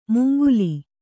♪ munguli